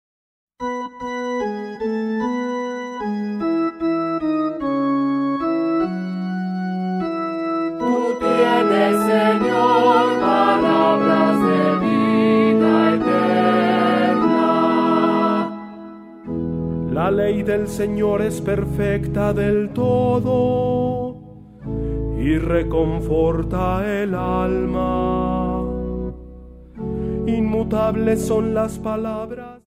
28 Vigilia Pascual 6a Lectura.